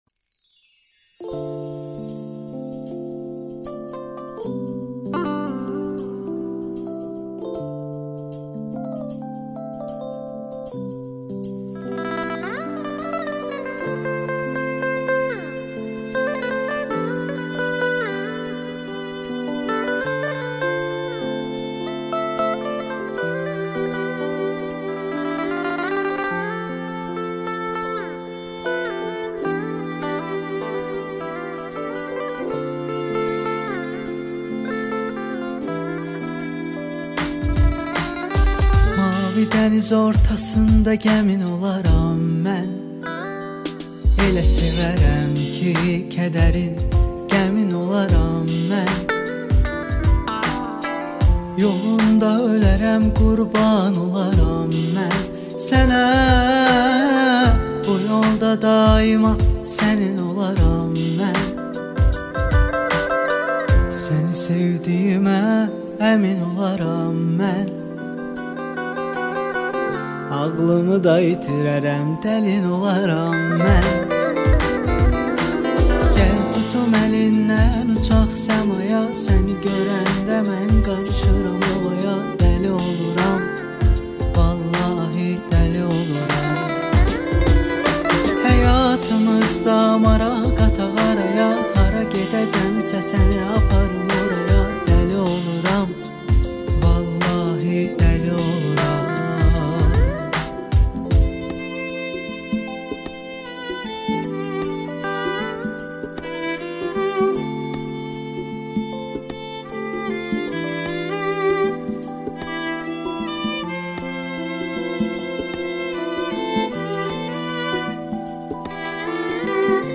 • Жанр: Турецкая музыка